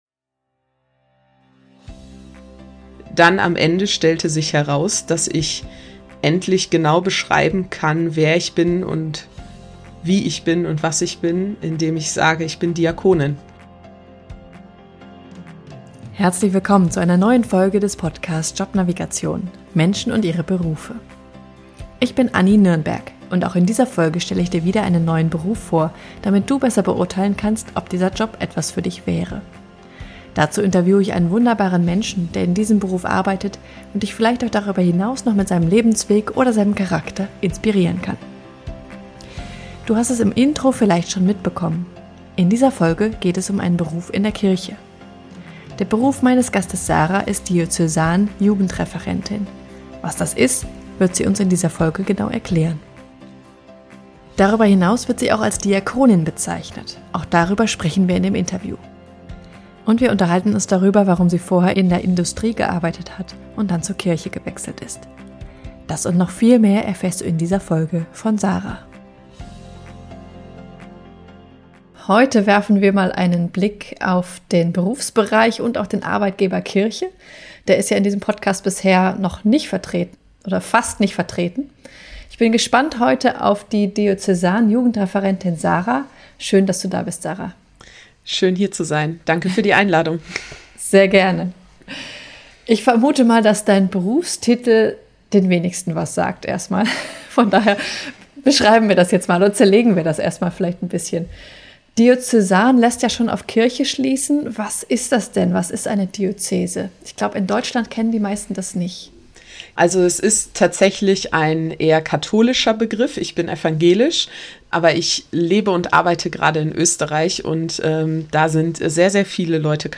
Sie wird auch als Diakonin bezeichnet, auch darüber sprechen wir im Interview.